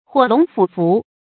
火龍黼黻 注音： ㄏㄨㄛˇ ㄌㄨㄙˊ ㄈㄨˇ ㄈㄨˊ 讀音讀法： 意思解釋： 《左傳·桓公二年》：「火龍黼黻，昭其文也。」